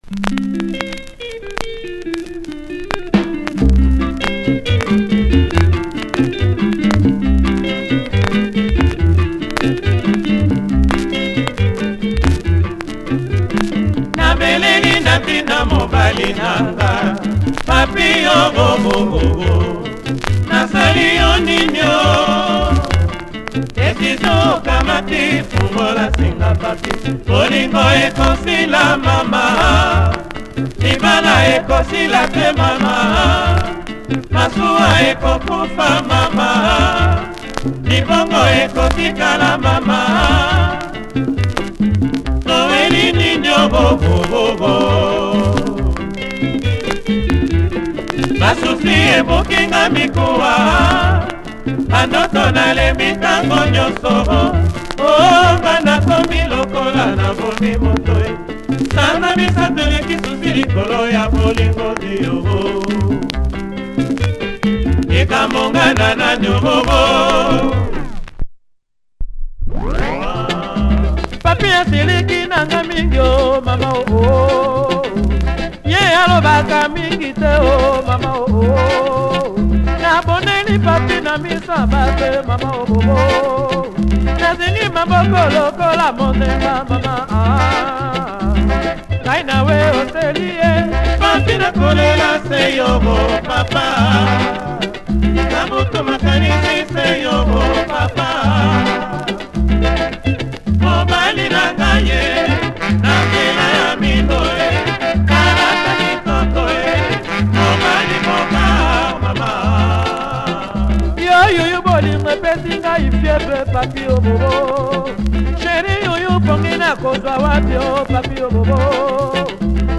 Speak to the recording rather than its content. Check the audio, slightly off center pressing.